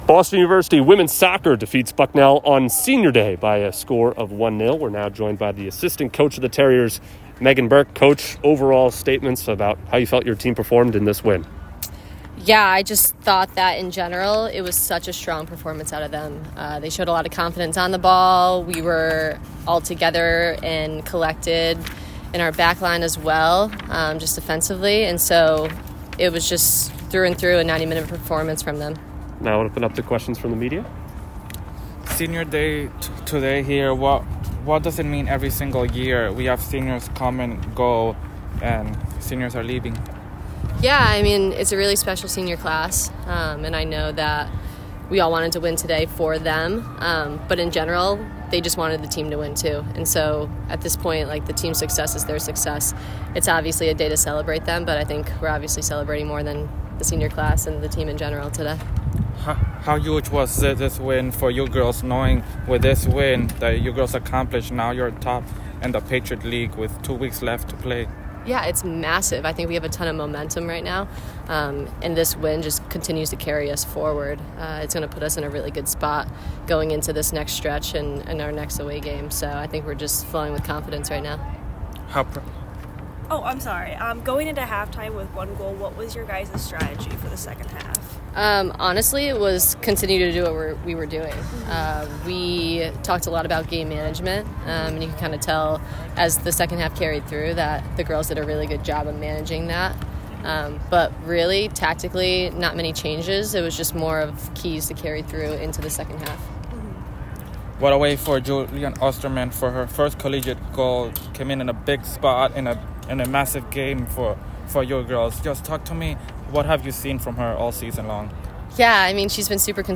WSOC_Bucknell_Postgame.mp3